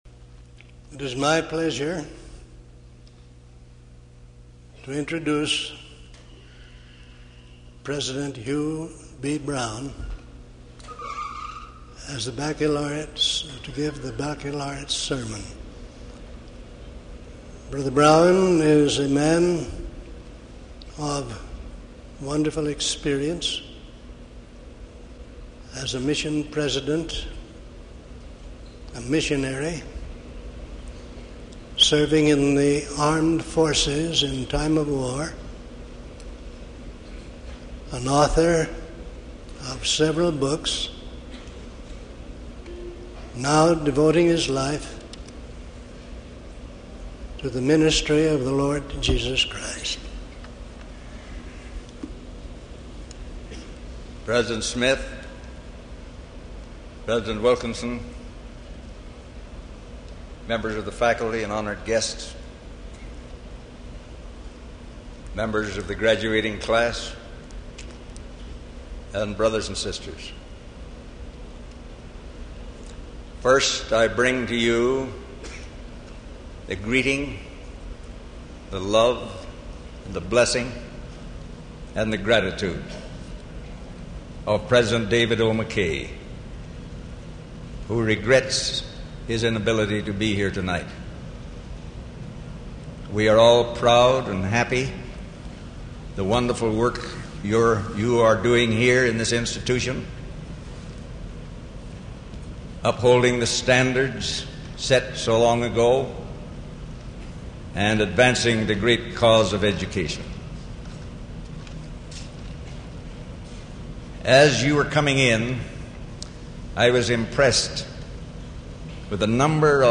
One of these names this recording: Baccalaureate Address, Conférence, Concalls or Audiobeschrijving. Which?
Baccalaureate Address